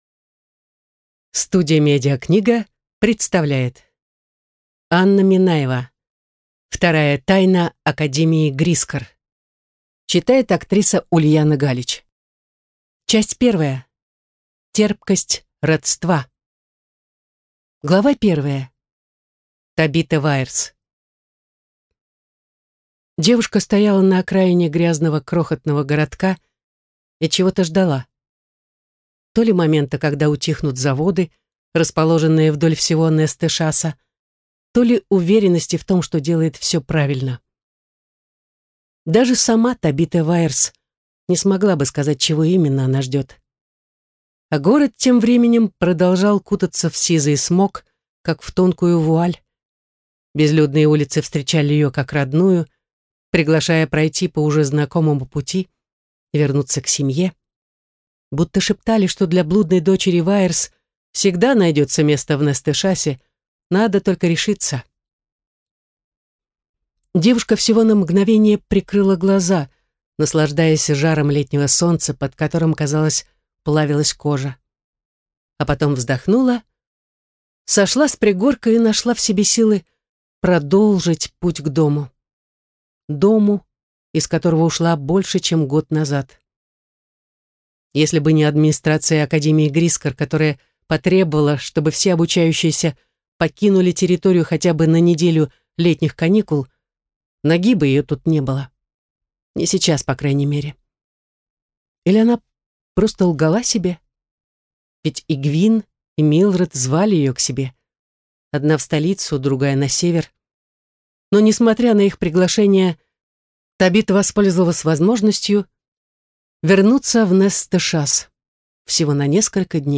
Аудиокнига Вторая тайна академии Грискор | Библиотека аудиокниг